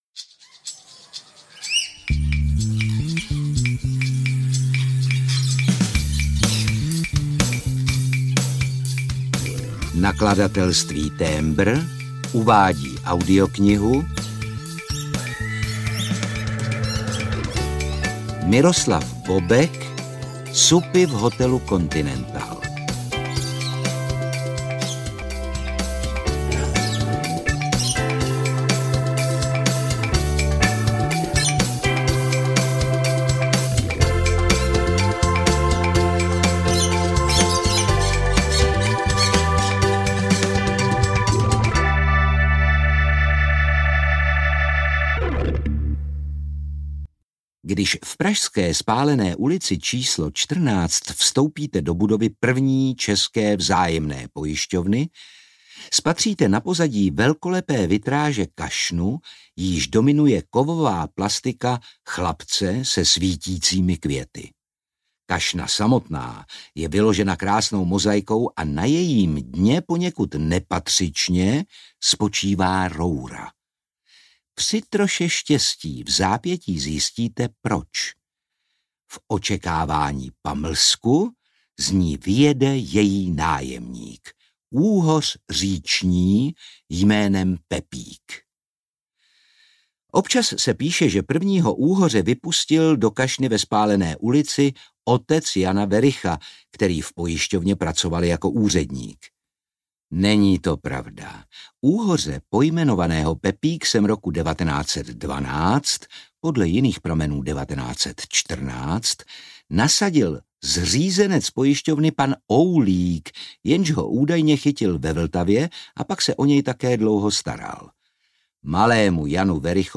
Ukázka z knihy
• InterpretOtakar Brousek ml.